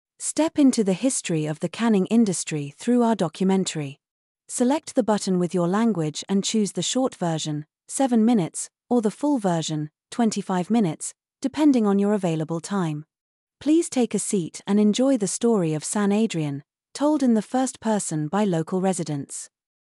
Descubre el documental sobre la historia conservera de San Adrián, narrada por sus vecinos en un emotivo viaje al pasado.